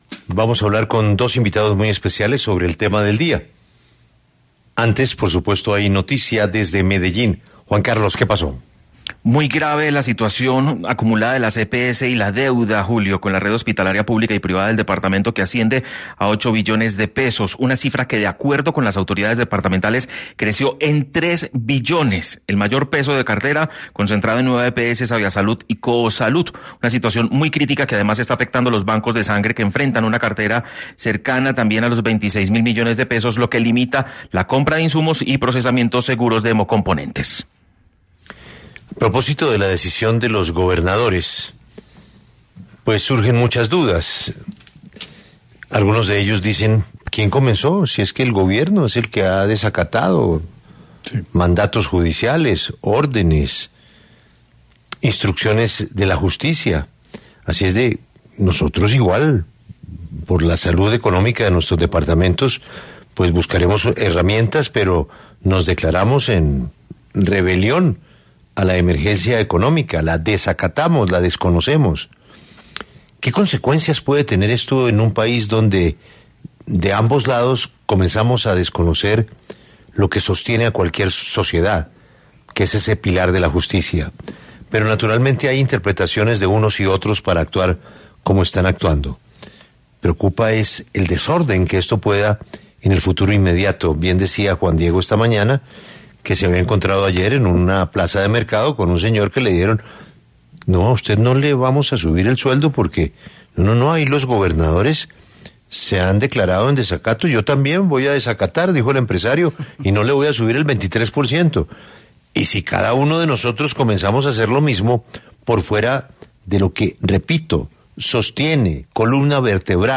Debate de expertos: ¿Es legal la ‘rebelión’ de gobernadores a la emergencia económica del Gobierno?